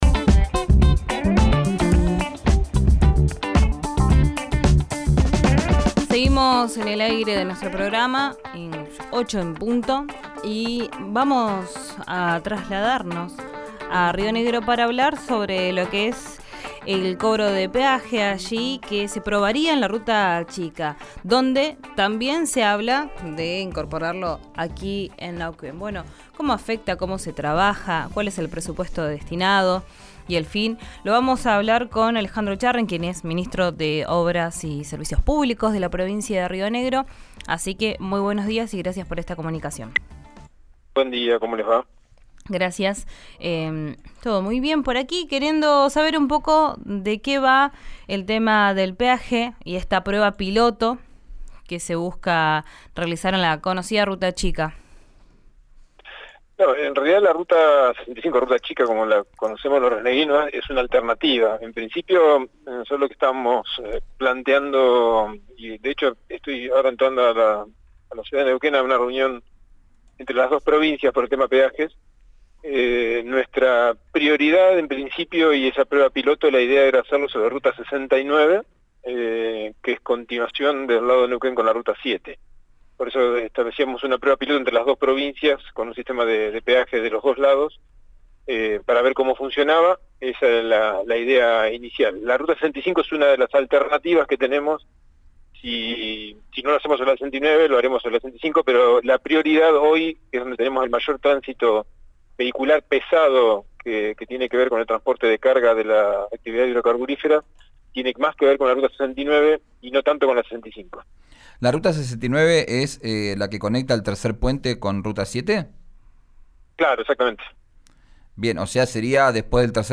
Escuchá al ministro de Obras Públicas de Río Negro, Alejandro Echarren, por RÍO NEGRO RADIO.